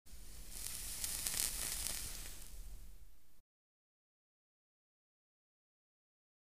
Sizzle; Cigarette Puff Fades In And Out